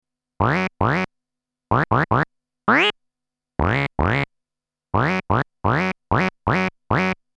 Как синтезировать манок утки.
Там, вроде FM модуляция(наверно) и ещё что-то.